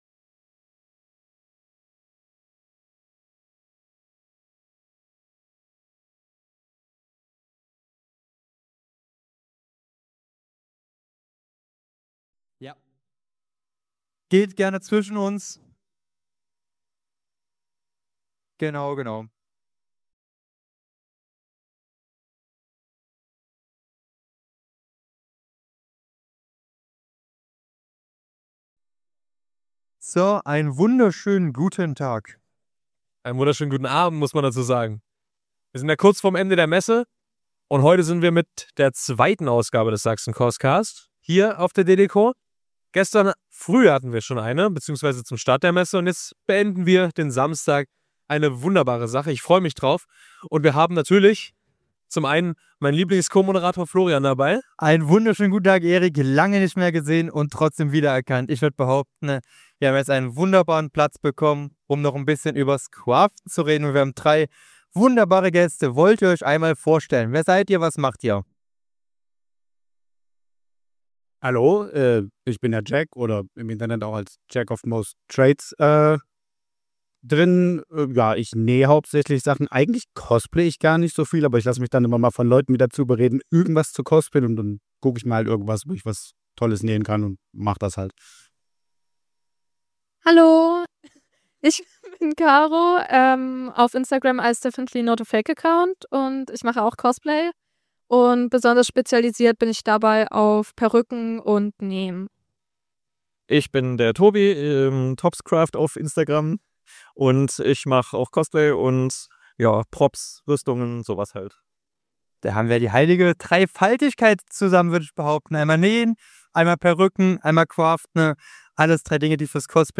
Folge 20 | Live | Wochenendtalk mit Craftern auf der DeDeCo 2026 ~ Sachsen-CosCast Podcast
Diese Live Episode des Sachsen-CosCast fand ebenso auf der Loki Stage der DeDeCo 2026 statt.